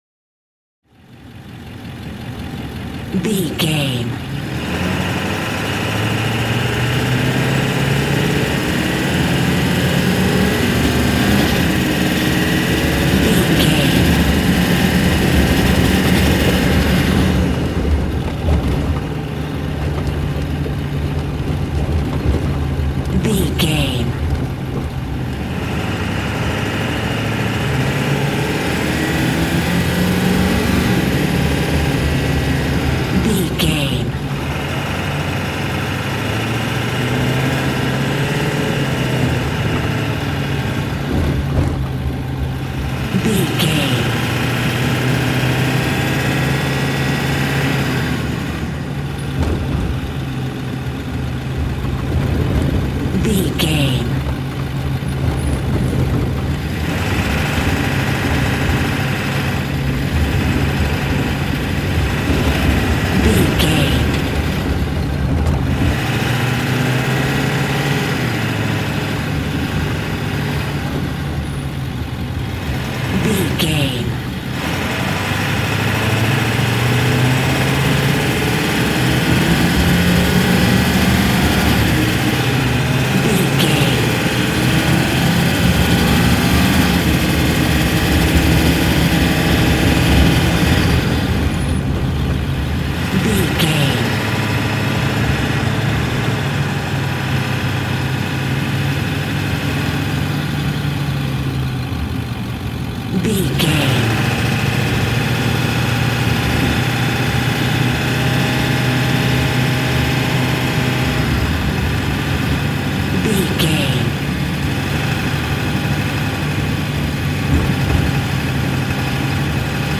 Ambulance Int Drive Diesel Engine Accelerate Fast
Sound Effects
chaotic
emergency